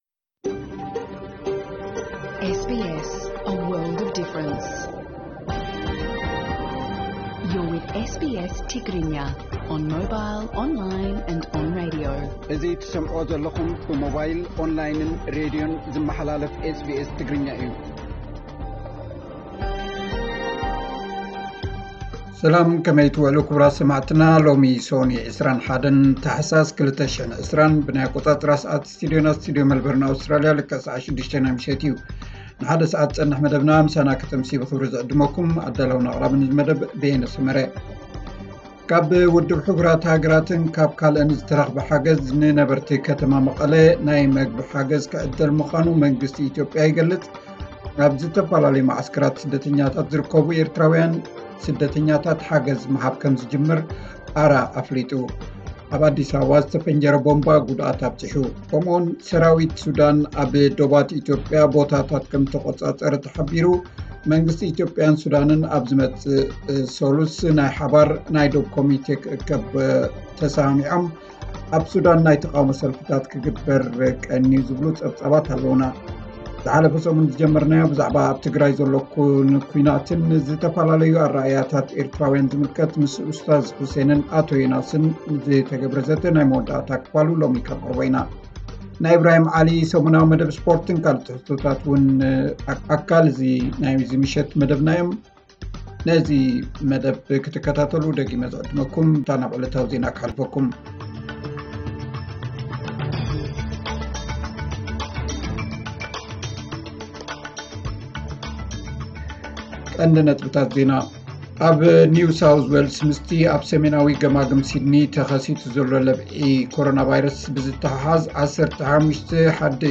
ዕለታዊ ዜና 21 ታሕሳስ 2020 SBS ትግርኛ